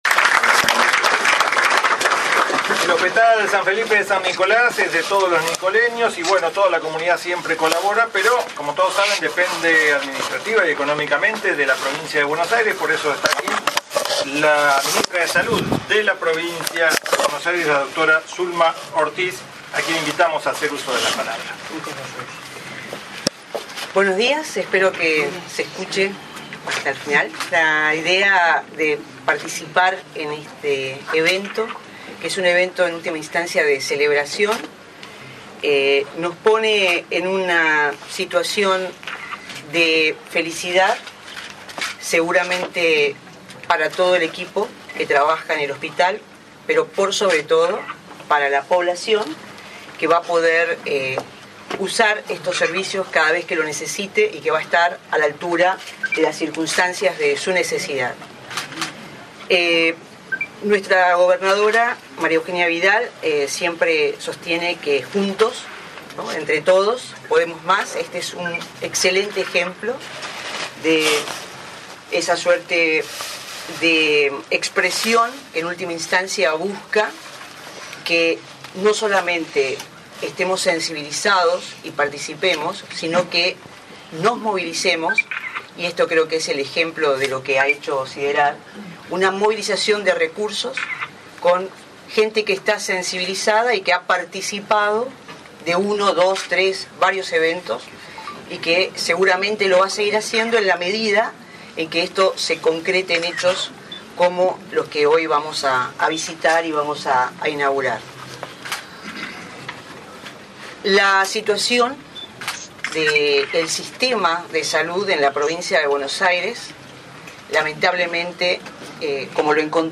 Se realizó un descubrimiento de placa y recorrida de las instalaciones con motivo de la finalización de las obras de puesta en valor del Servicio de Clínica y de Cirugía del Hospital, llevadas a cabo gracias al aporte solidario de la Maratón 10K Ternium realizada en octubre del año pasado.
Audio:  Ministra de Salud de la Provincia Dra. Zulma Ortiz